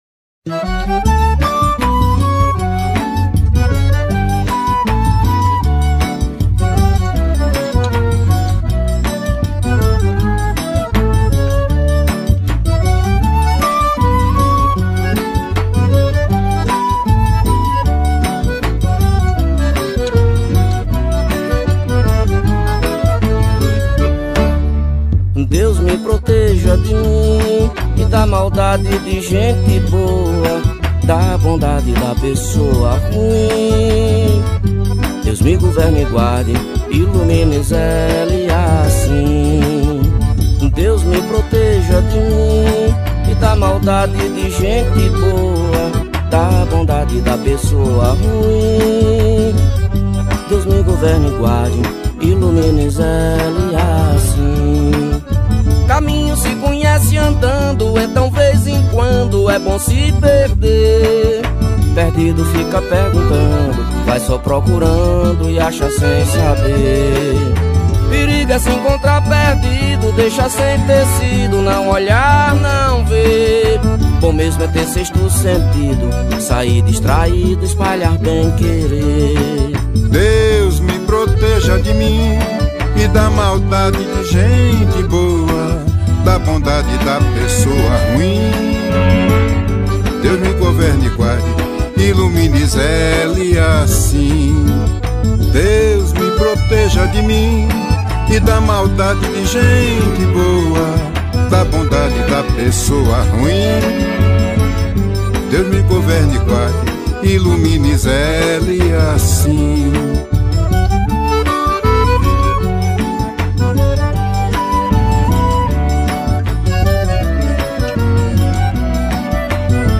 Deus-Me-Proteja-Forro-Stream.mp3